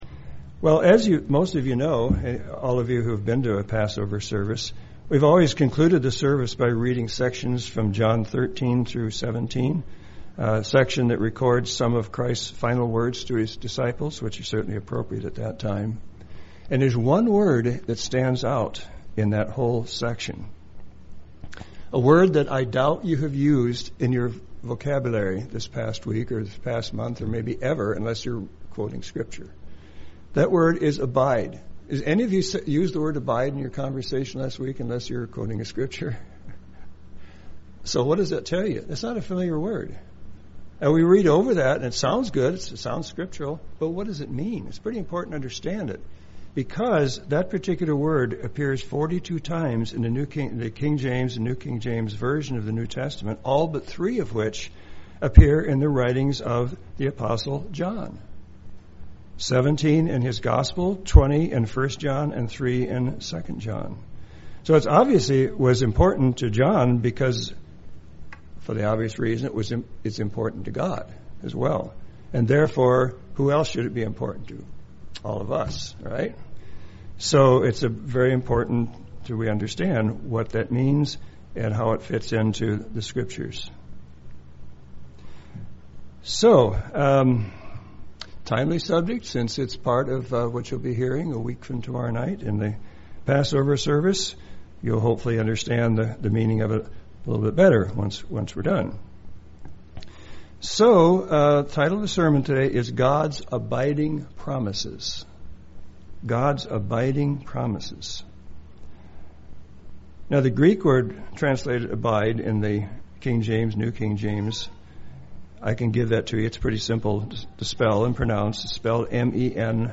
Sermons
Given in Medford, OR